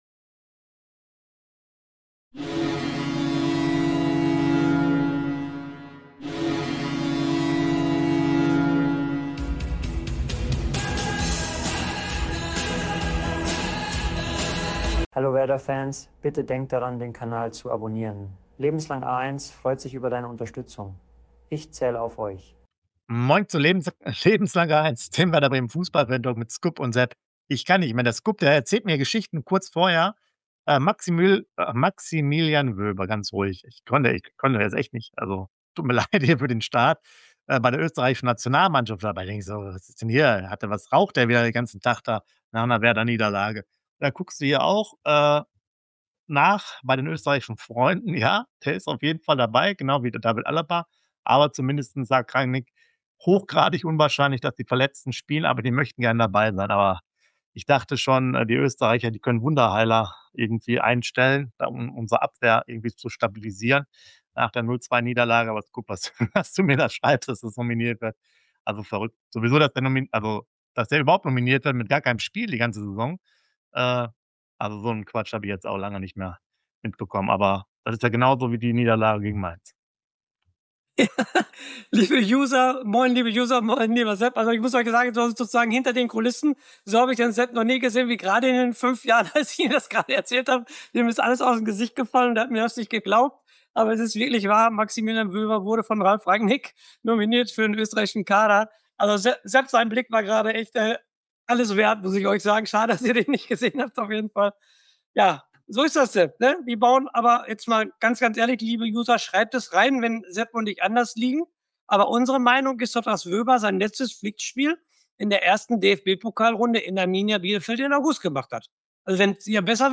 Fantalk